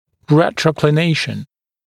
[ˌretrə(u)klɪ’neɪʃn][ˌрэтро(у)кли’нэйшн]ретроклинация, наклон в небную/лингвальную сторону
retroclination.mp3